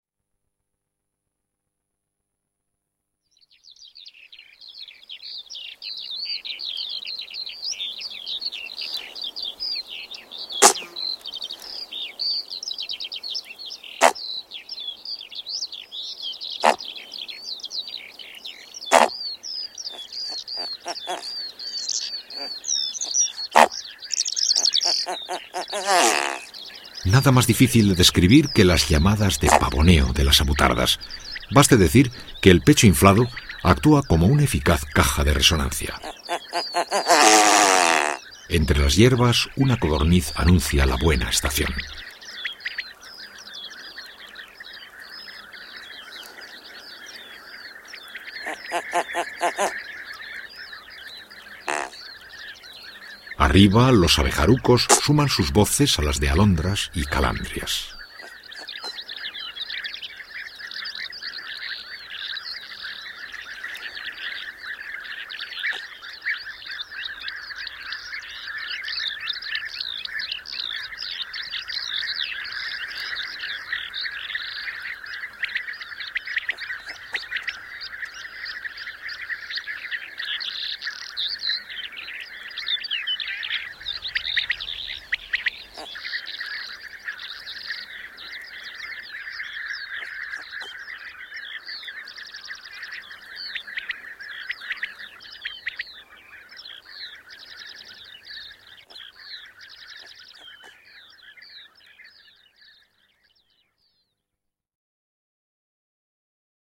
El Sonido de los Parques Nacionales es una colección realizada en el año 2003 por Carlos de Hita con las narraciones de Iñaki Gabilondo.
Abril, la rueda de la Avutarda, media mañana en la raña (01:45)